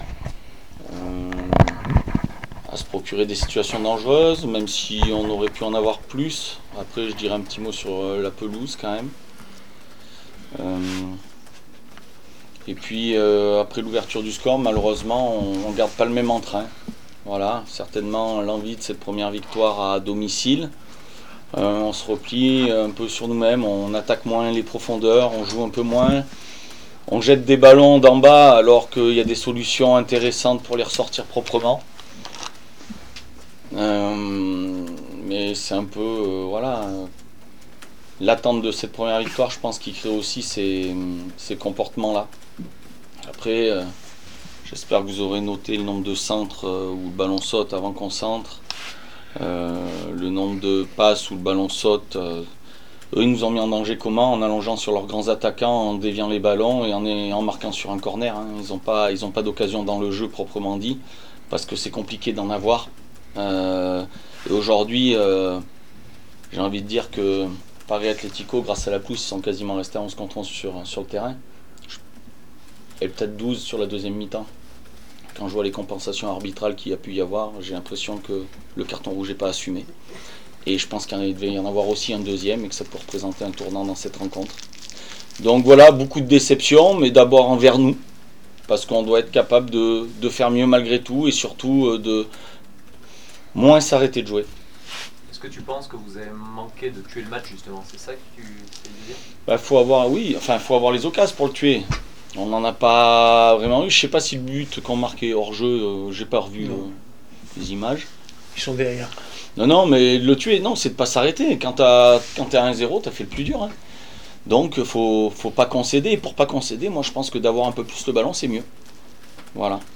27 septembre 2025   1 - Sport, 1 - Vos interviews